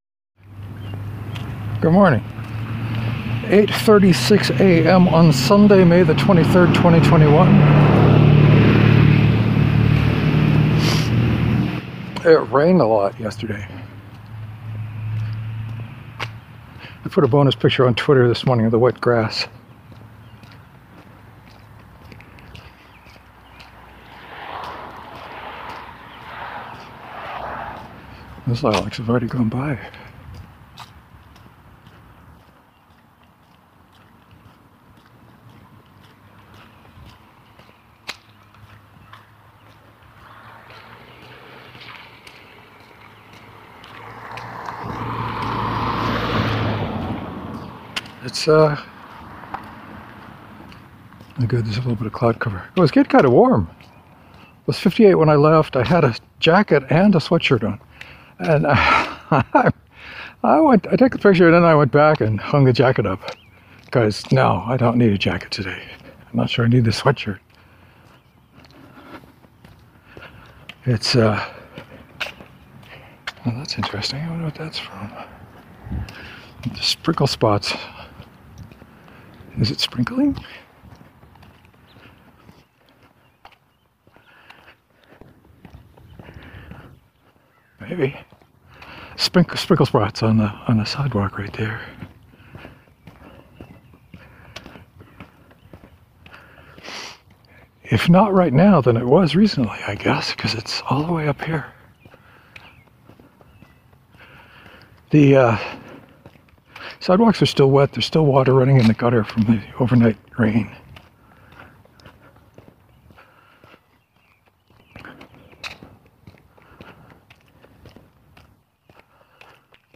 Much warmer than yesterday. Apparently I just walked along enjoying the weather for a full minute between 12 and 13.